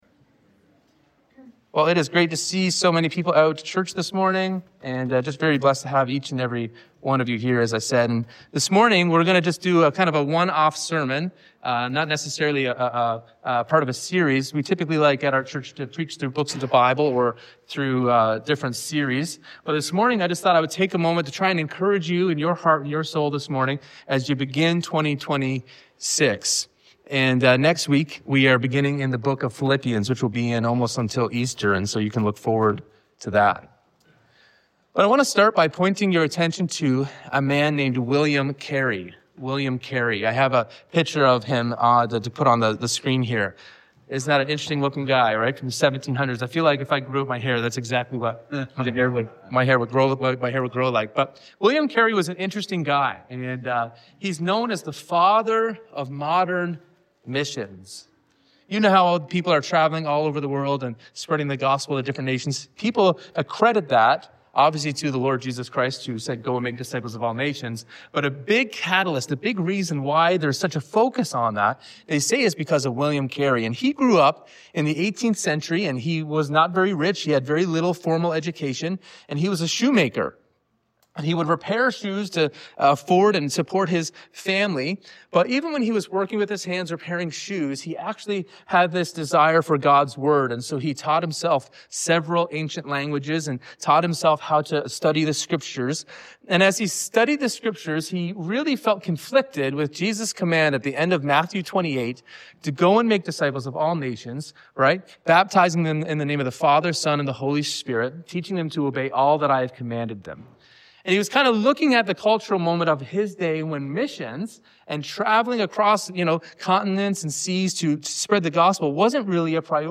This sermon encourages weary hearts to hope again, make room for God’s work, and remain deeply rooted in Christ as He builds His kingdom.